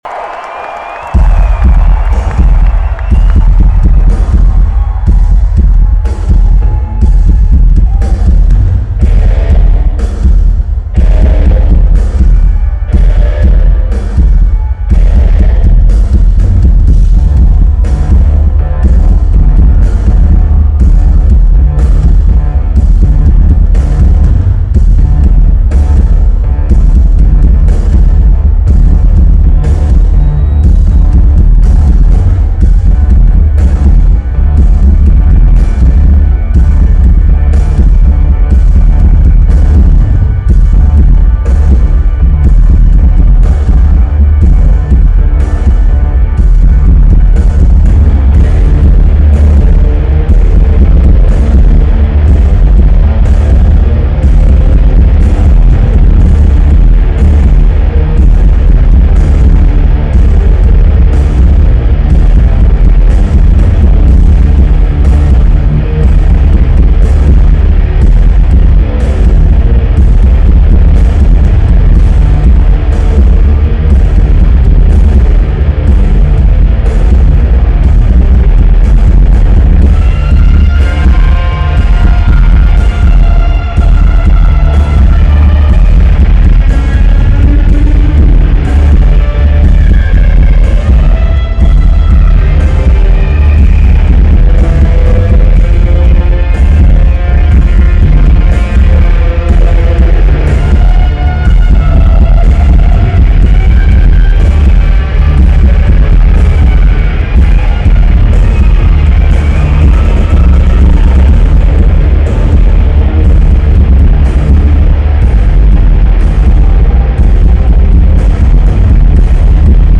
Oracle Arena
Lineage: Audio - AUD (Stock M-Audio Mic + Microtrack)